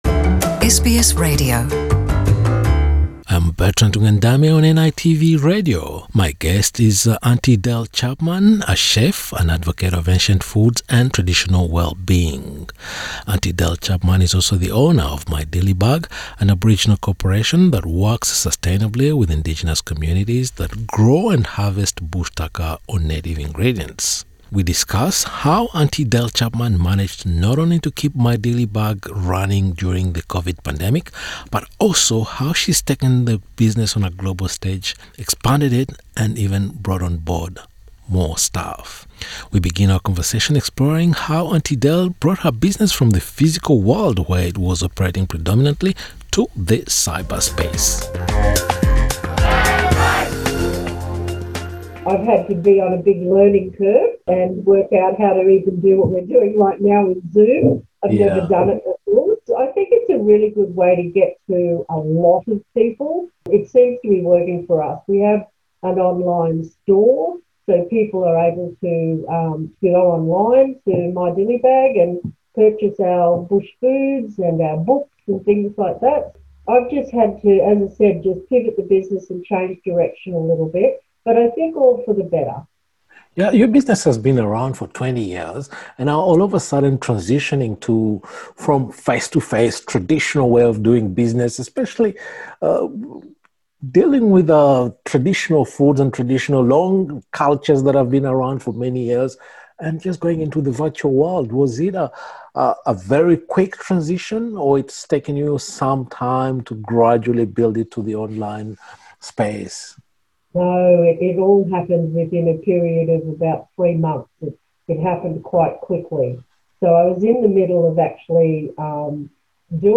In a conversation with NITV Radio